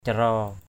/ca-rɔ:/ 1.
caraow.mp3